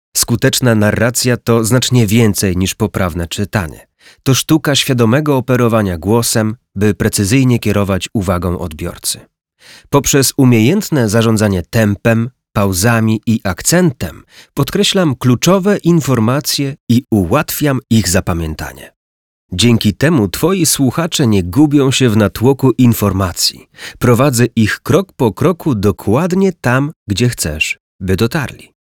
Narracje lektorskie do szkoleń, onboardingów, szkolenia, BPH, prezentacji